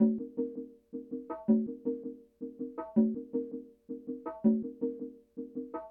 • bongos and congas sample 1.wav
bongos_and_congas_sample_1_93v.wav